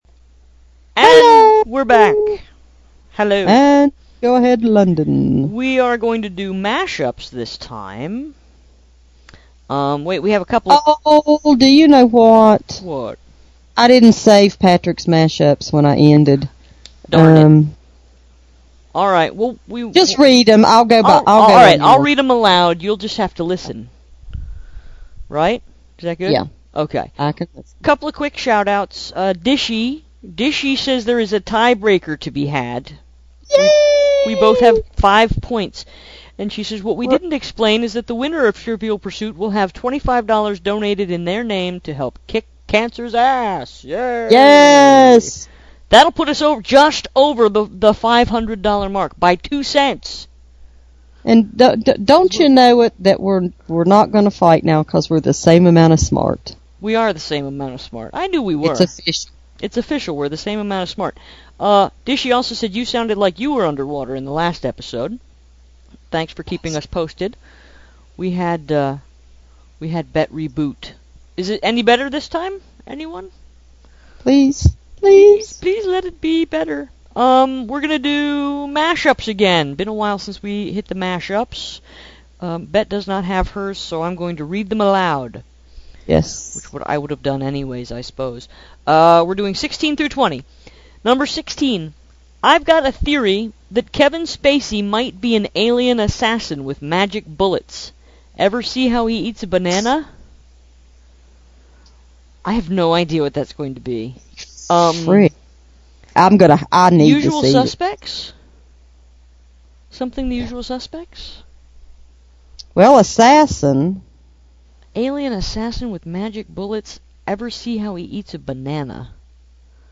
blogathon-24.mp3: Time for more Movie Mash-ups! Lots of dead air in this episode. That’s the sound of us thinking.
No underwater.